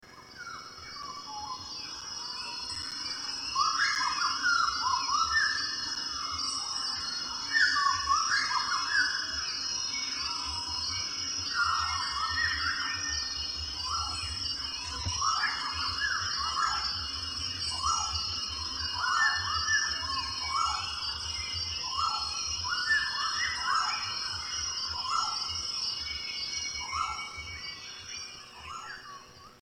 Rainforest Ambience 4
Category: Animals/Nature   Right: Personal